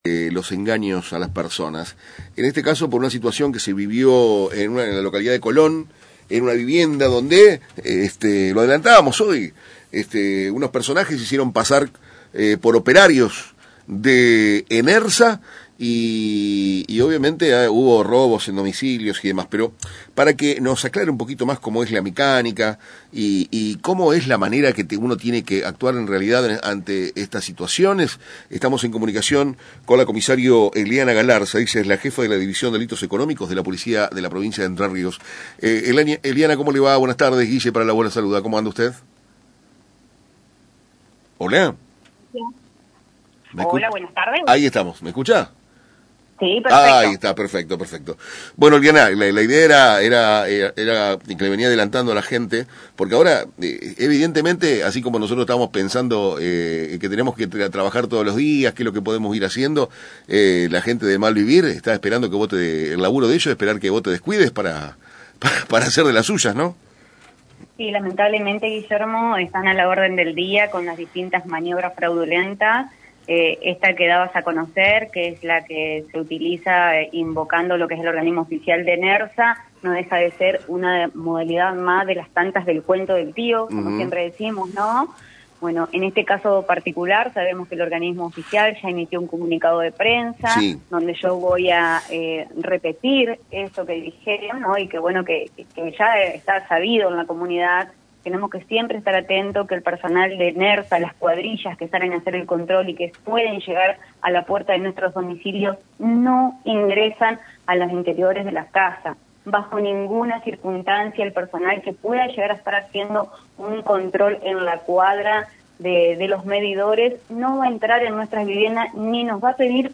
ESCUCHA LA NOTA COMPLETA en Para Temprano es Tarde